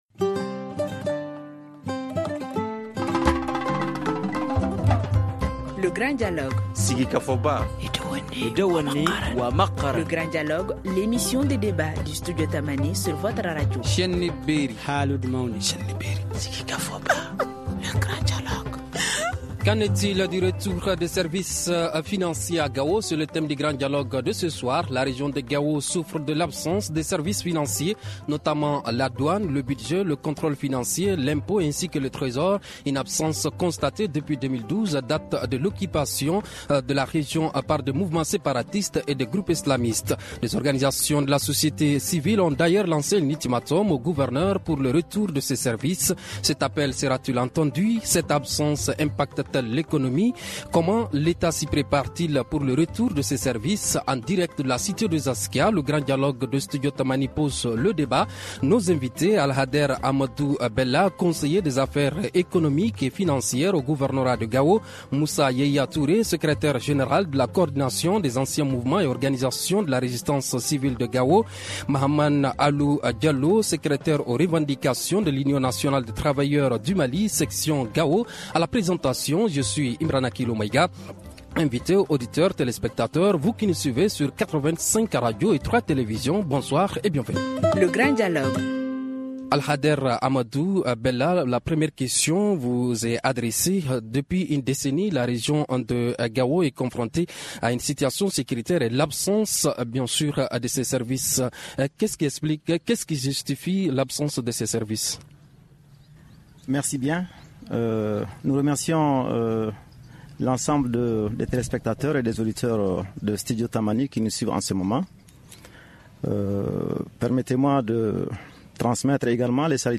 En direct de la cité des askias, le Grand Dialogue pose le débat